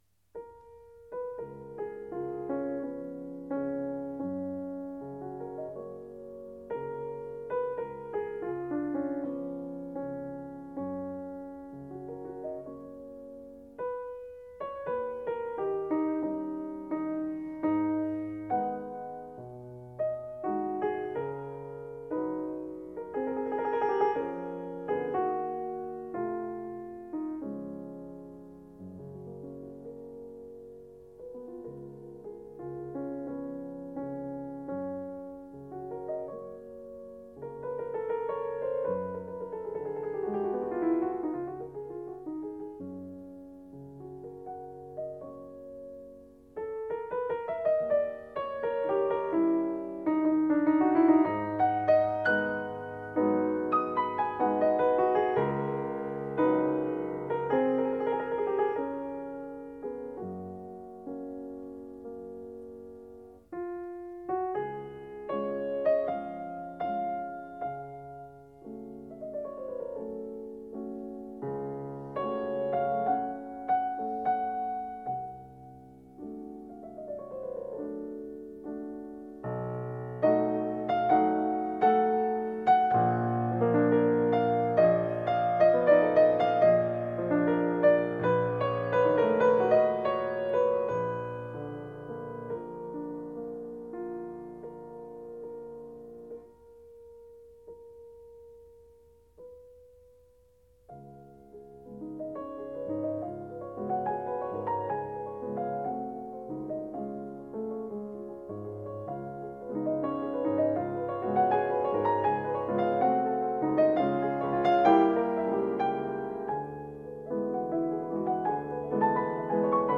极慢板，先是装饰的花音，然后是丰富的情绪，这是最优美的一首夜曲。